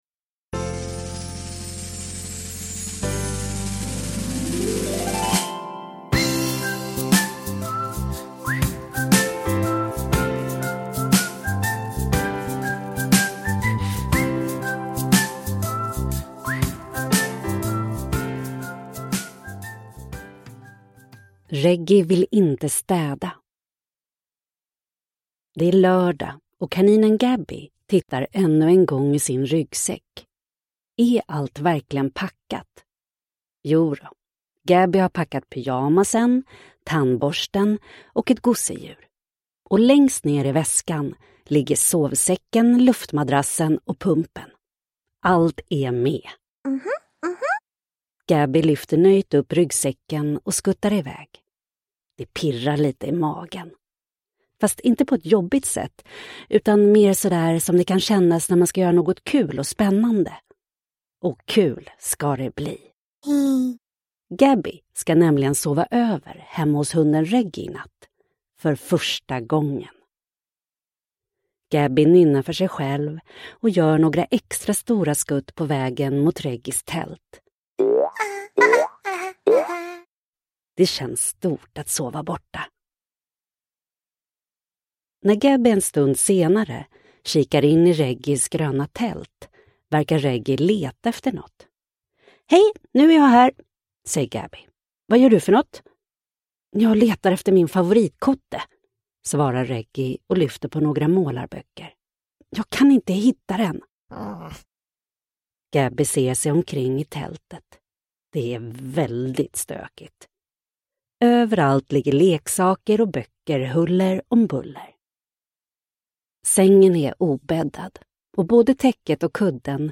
Reggy vill inte städa – Ljudbok – Laddas ner